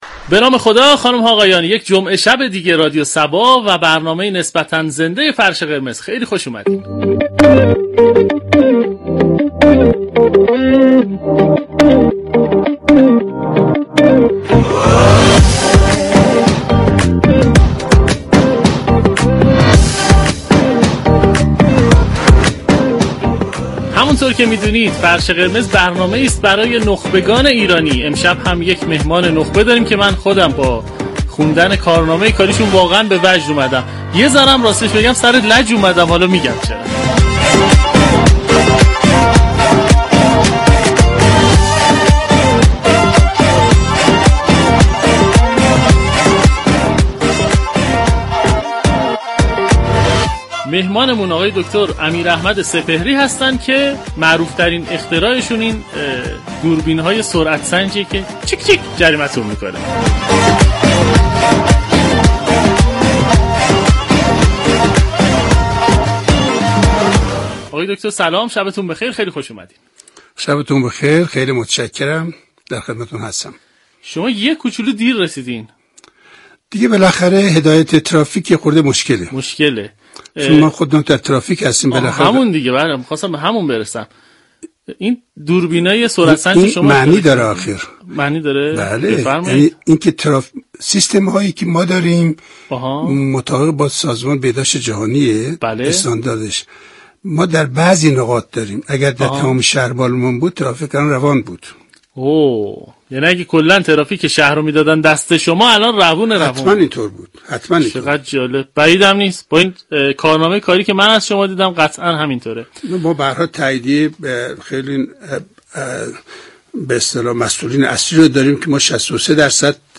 به گزارش روابط عمومی رادیو صبا، «فرش قرمز» عنوان یكی از برنامه‌های گفت‌وگو محور این شبكه رادیویی است كه جمعه شب‌ها میزبان نخبگان خوب كشور ما می‌شود.